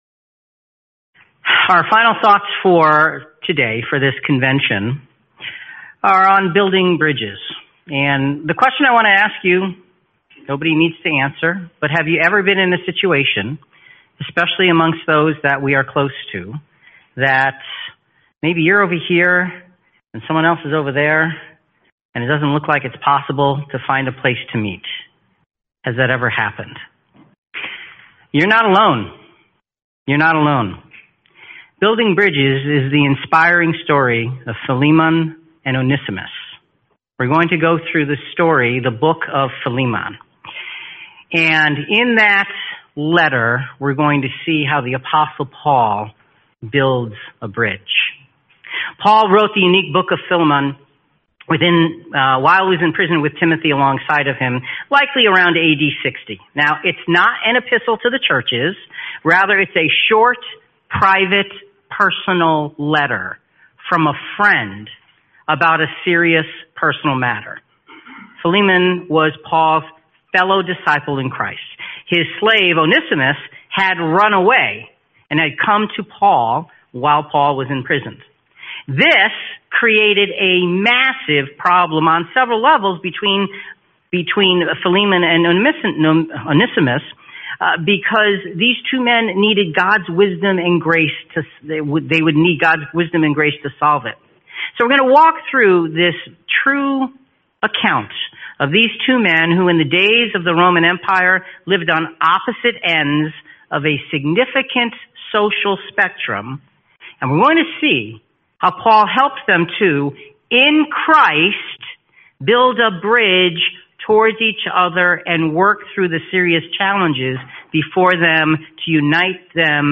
Series: 2026 Phoenix Convention